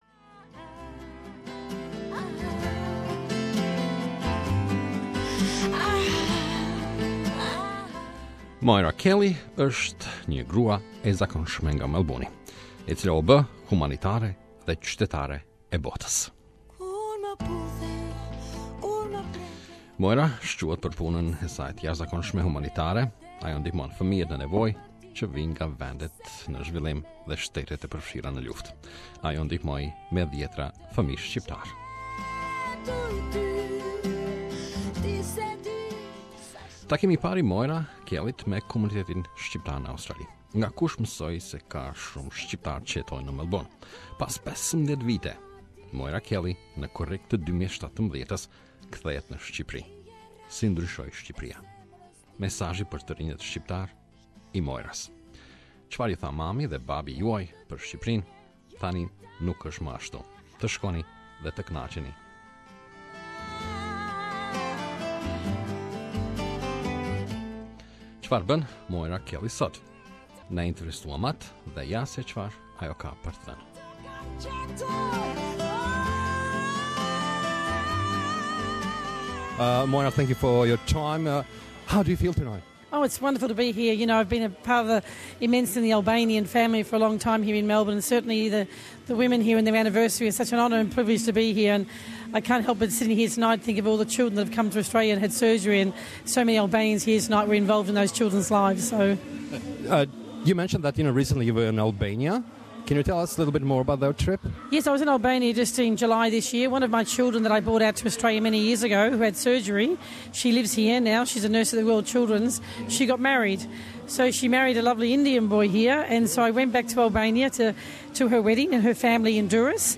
We interviewed Moira Kelly and here is what she has to say.